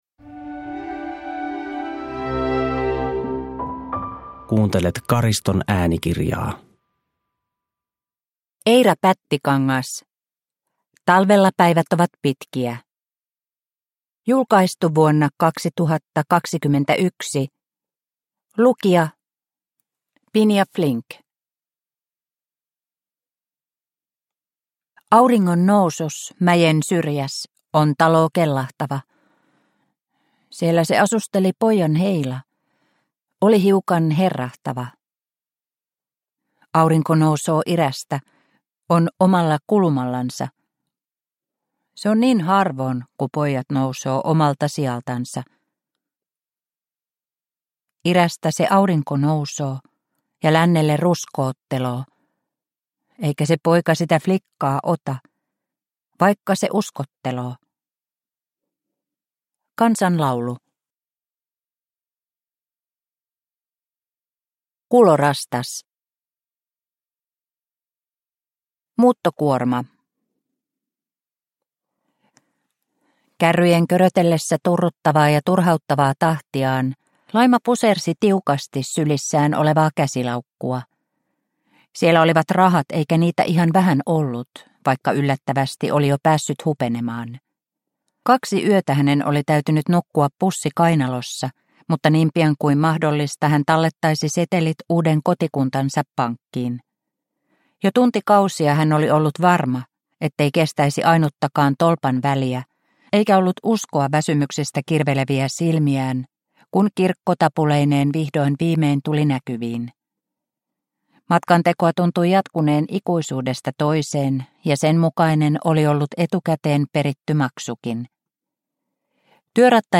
Talvella päivät ovat pitkiä – Ljudbok – Laddas ner